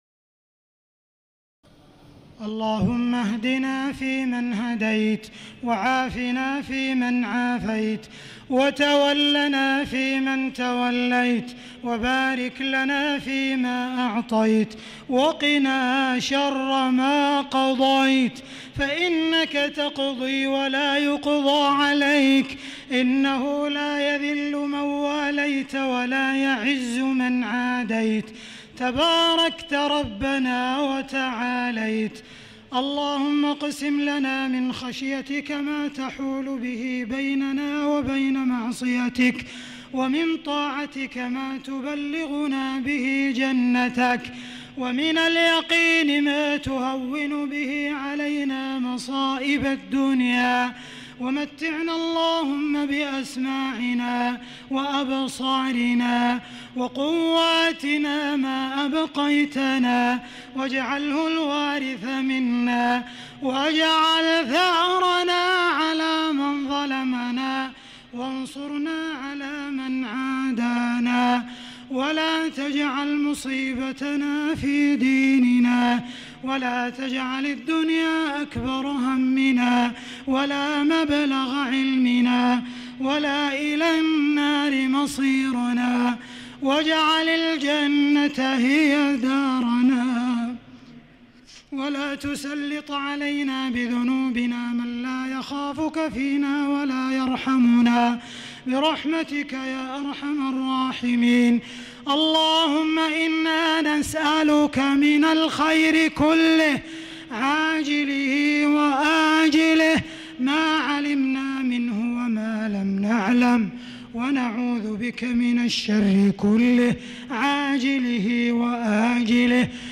دعاء القنوت ليلة 3 رمضان 1437هـ | Dua for the night of 3 Ramadan 1437H > تراويح الحرم المكي عام 1437 🕋 > التراويح - تلاوات الحرمين